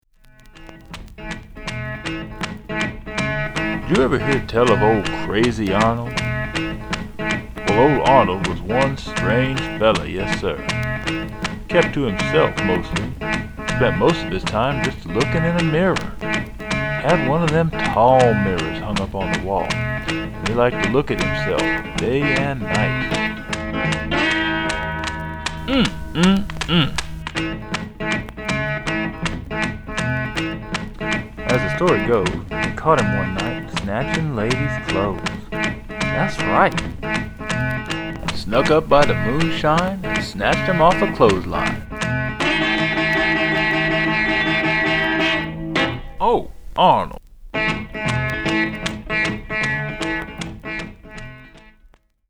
talking blues